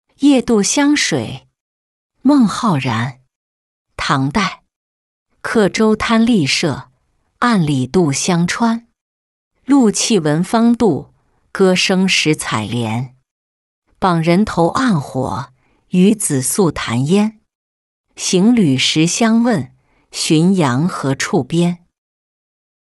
夜渡湘水-音频朗读